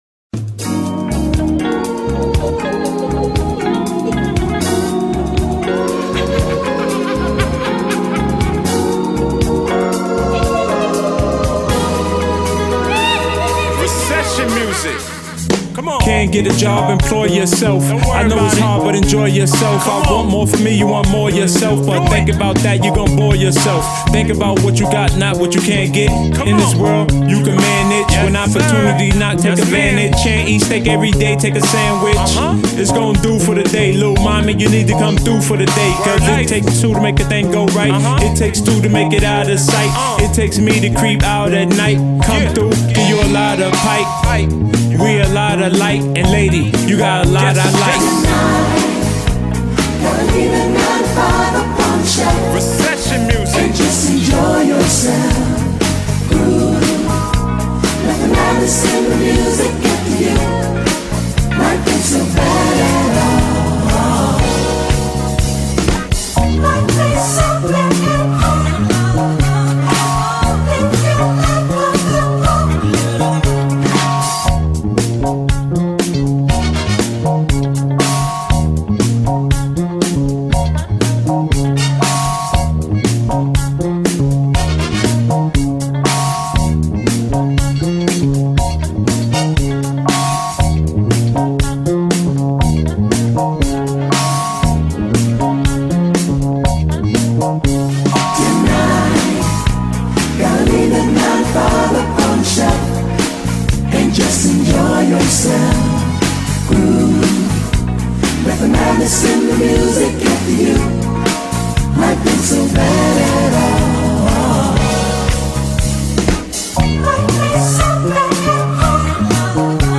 dance/electronic
tribute music